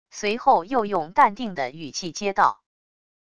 随后又用淡定的语气接道wav音频